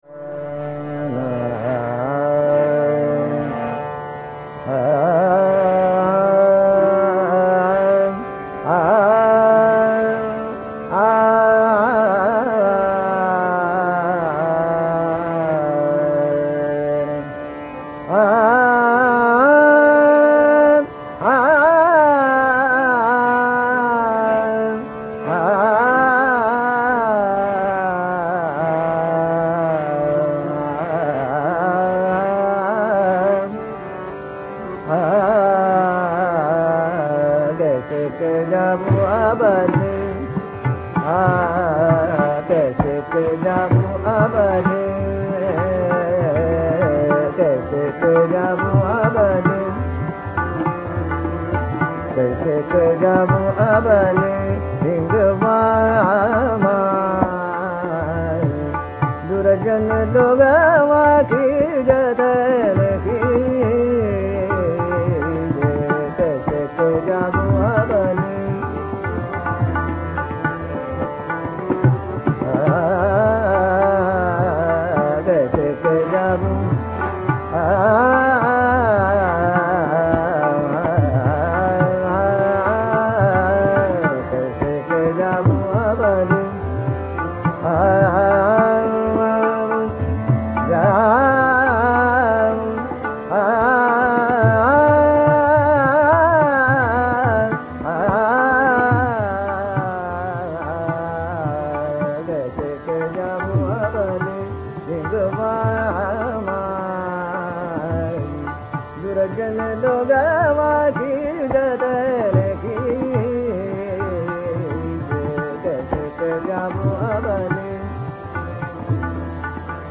Raga Lachari Todi
The development is grounded in Asavari.